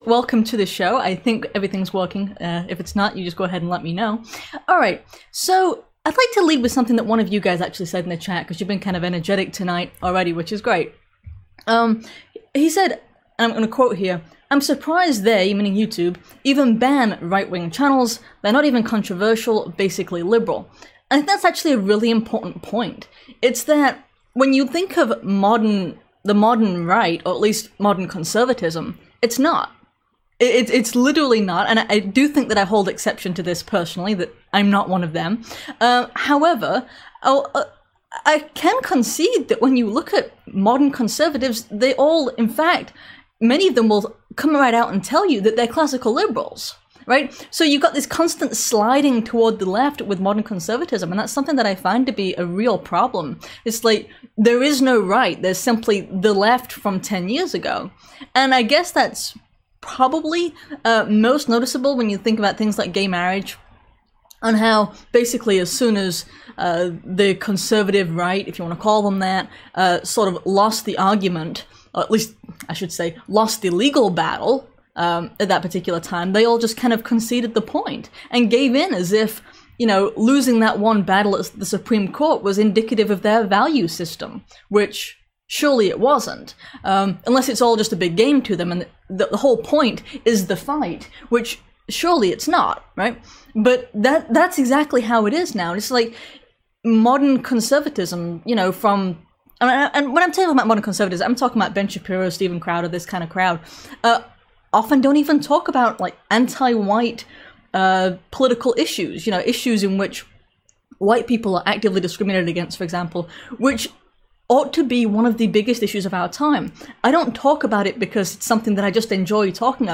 This live show covered Newsom's unsurprising victory in the fallen western zone, the treasonous general who has been embraced by the left, the New York governor who is targeting Christians, and more.